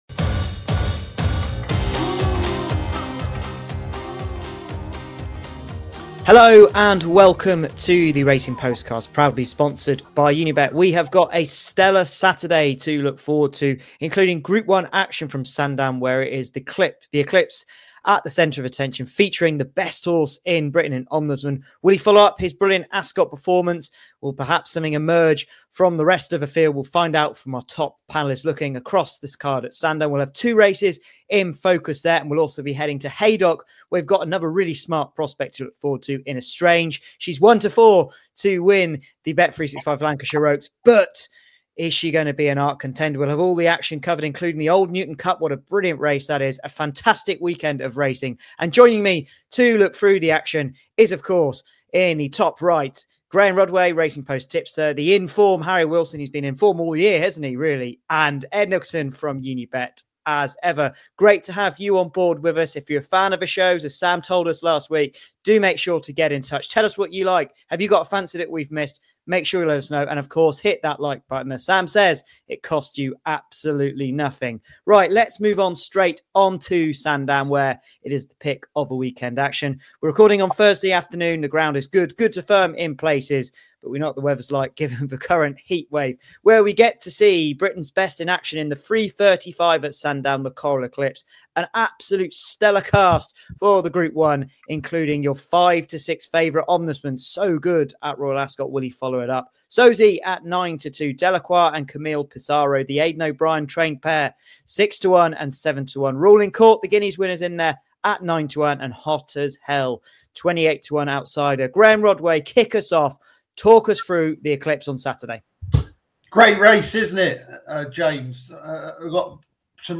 Welcome to the latest episode of the Racing Postcast as our expert panel preview the ITV races from Sandown and Haydock this weekend. Who will win the Coral-Eclipse?